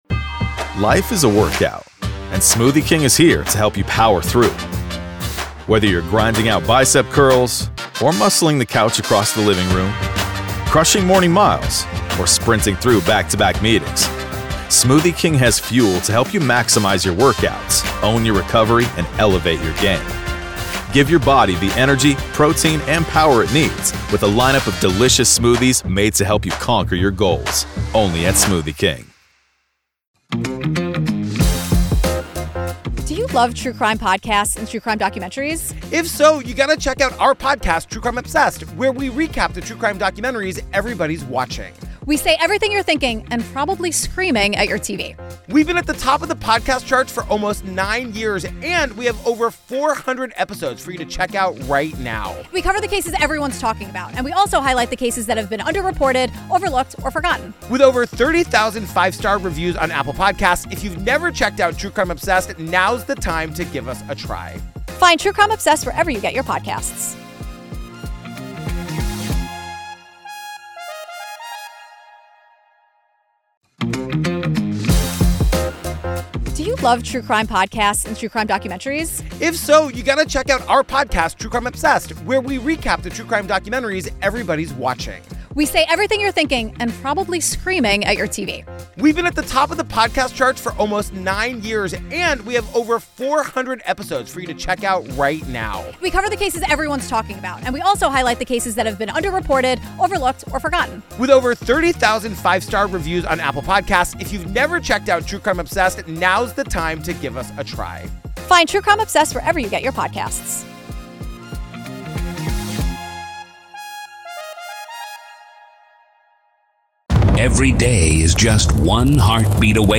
Retired FBI Special Agent